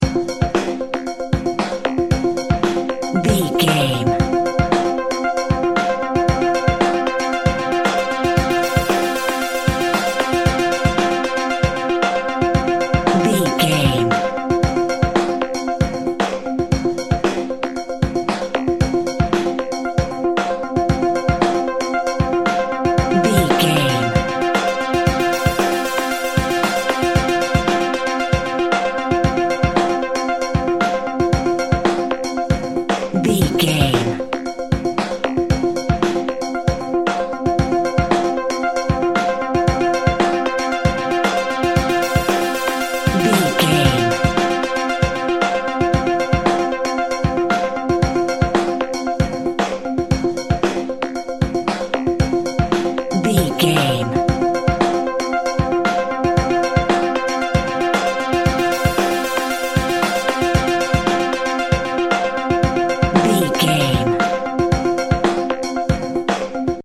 Ionian/Major
breakbeat
energetic
pumped up rock
power pop rock
synth lead
synth bass
synth drums